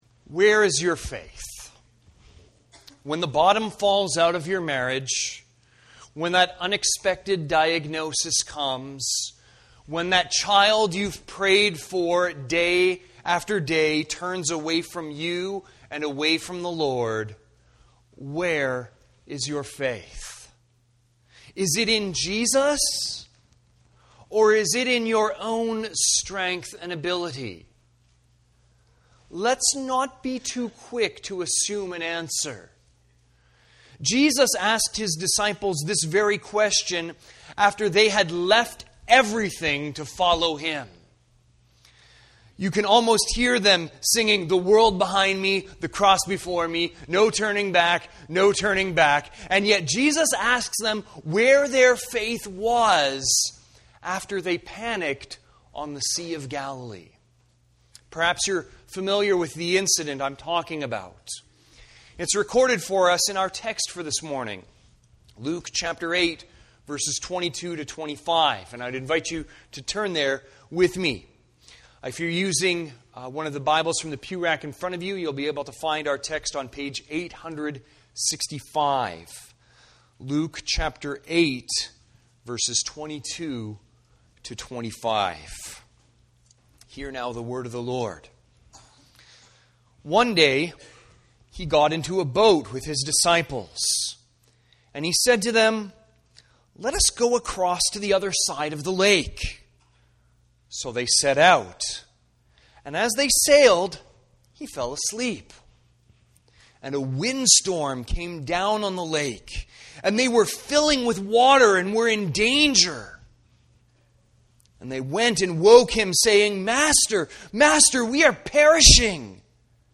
Sermons | Campbell Baptist Church